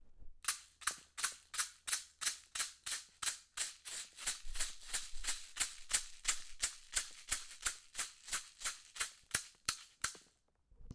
Hochet peau de bison ref. 9
Hochet chamanique artisanal en peau de cerf et bois de cerisier
Peau de bison glabre – bois de cerisier.
hochet-ref-9.mp3